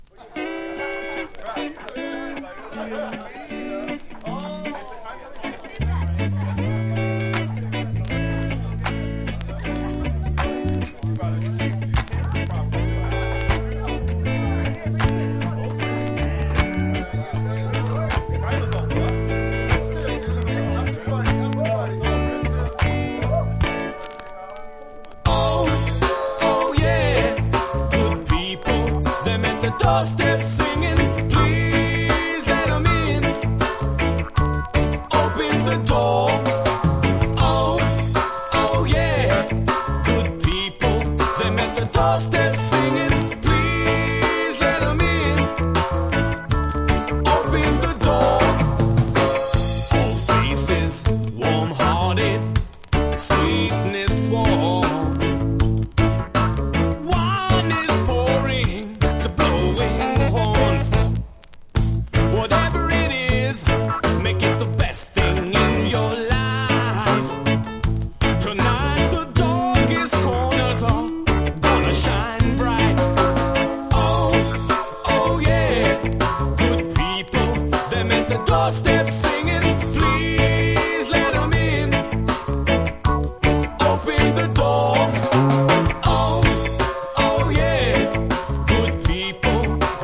• REGGAE-SKA
SKA / ROCK STEADY